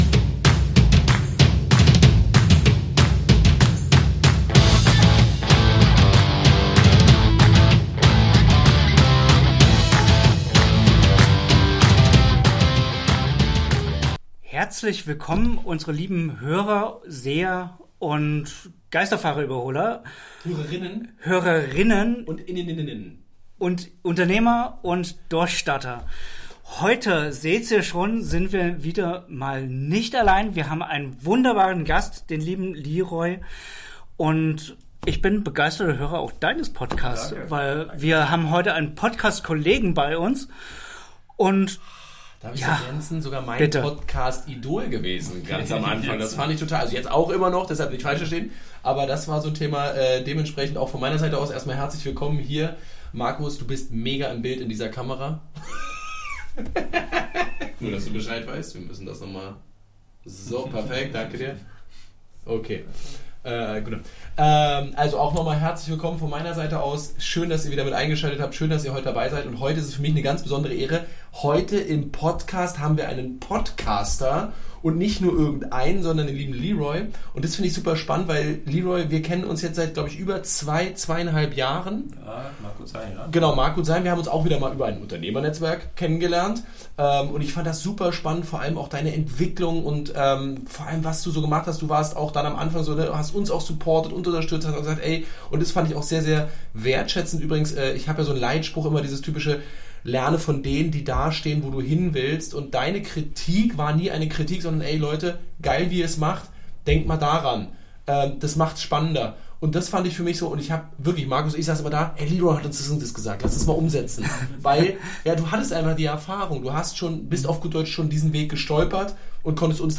Ein inspirierendes Gespräch über Mut, mentale Stärke und den Weg, aus einer Leidenschaft ein echtes Business aufzubauen.